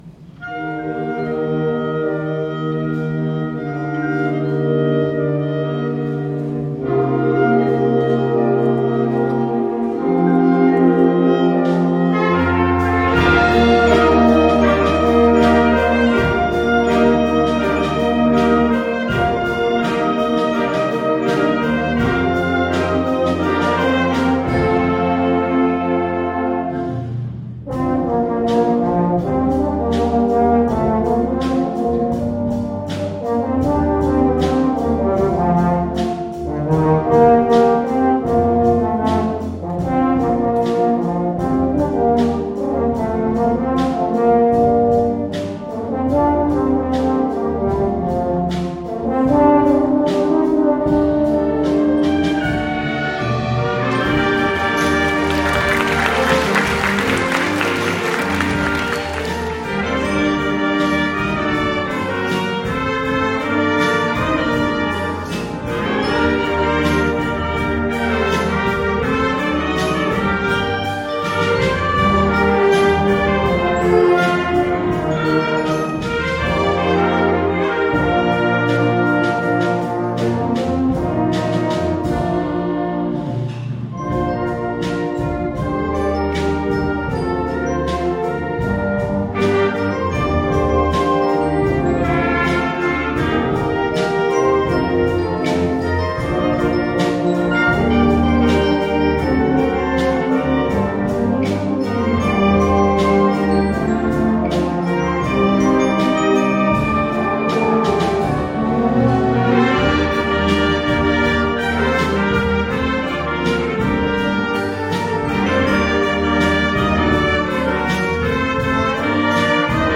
第41回文化発表会の舞台発表は、生徒会による活動報告と吹奏楽部の活気ある演奏で幕を開けました。
The stage performances at the 41st Culture Festival kicked off with a report on activities by the student council and a dynamic performance by the brass band.